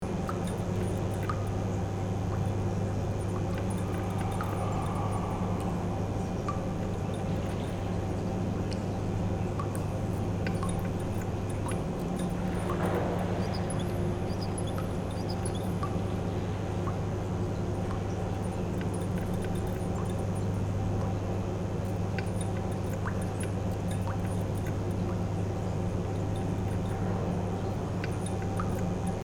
Horror-hospital-ambience-exploration-loop.mp3